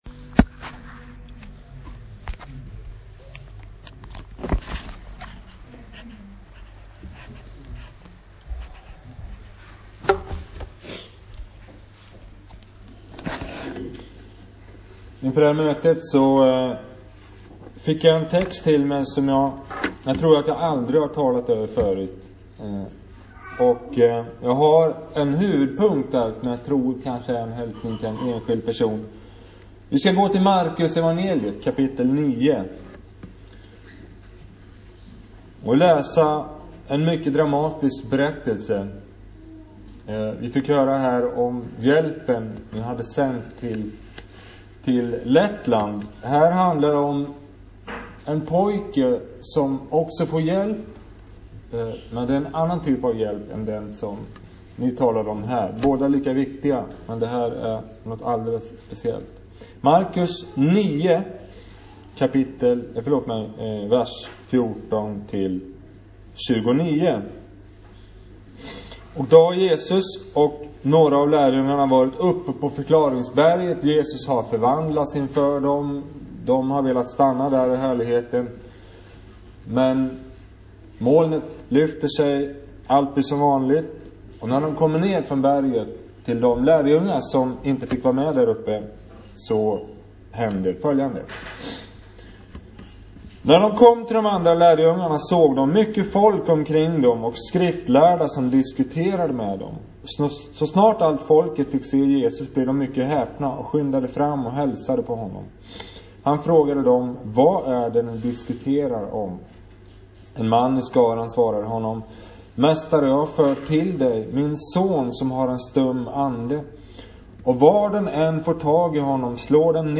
Predikoåret 2018